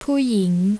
theoreitcally it is oo as in excrement, ying (rising tone), but practically, it comes out more like Poy (rhyming with boy, toy) ying (rising tone) ; this is due to the two words sitting together and thus the first vowel sound is somewhat shortened
phoo-ying.wav